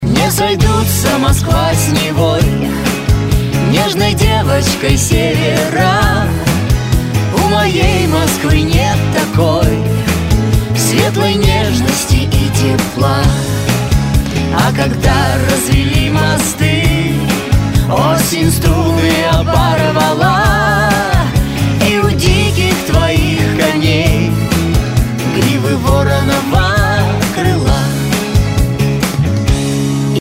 Шансон, Авторская и Военная песня